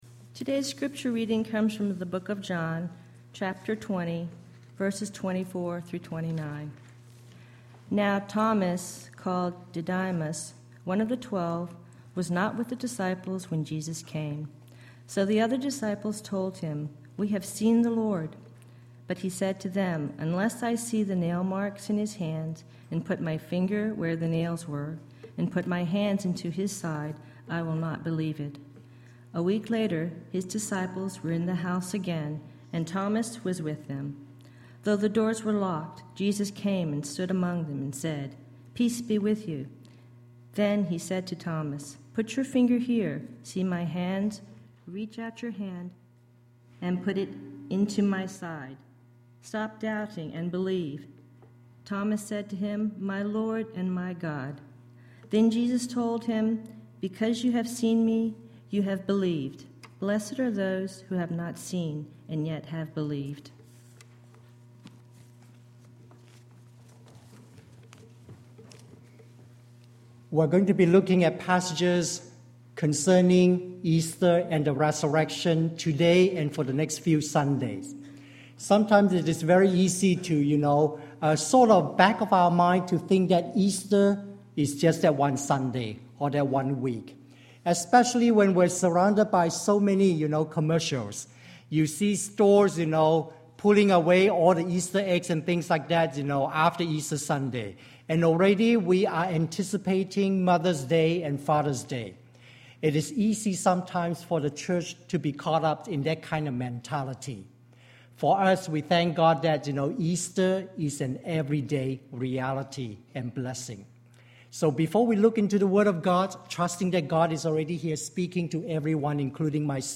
Presentation to accompany sermon